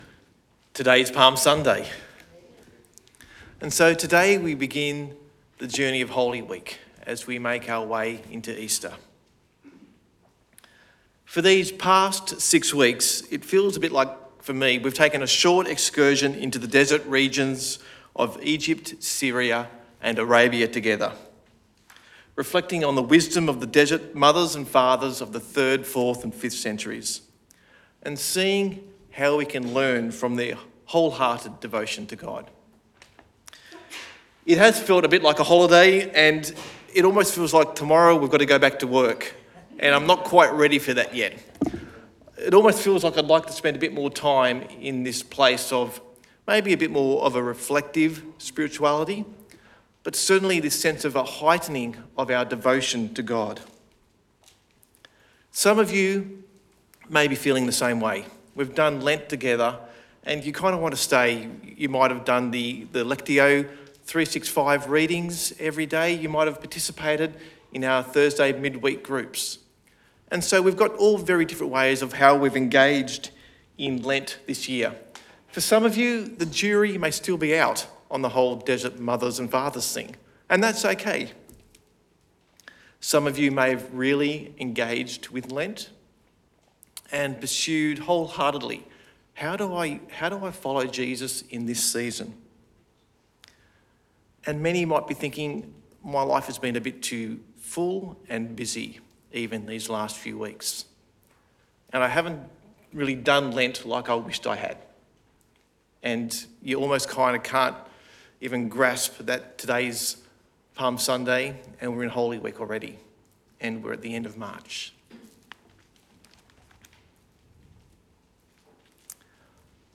Sermon Podcasts Wisdom in the Desert